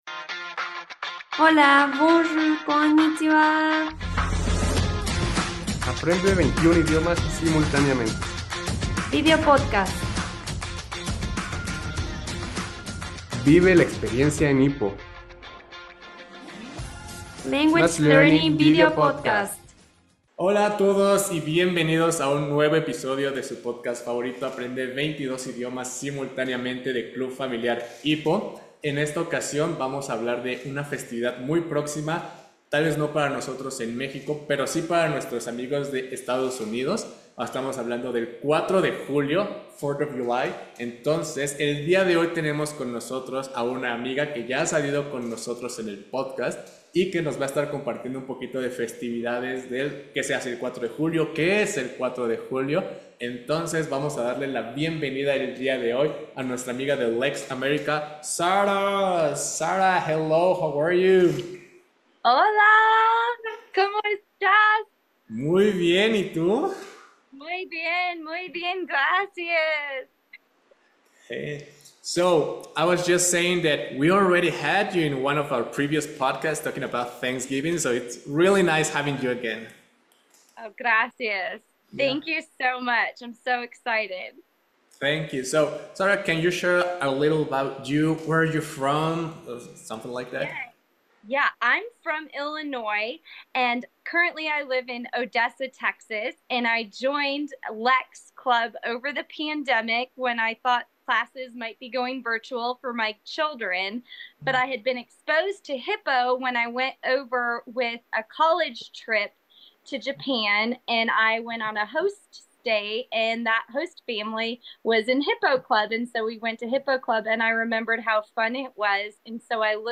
¡No te pierdas esta conversación fascinante llena de cultura, historia y diversión!